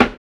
LO FI 4 SD.wav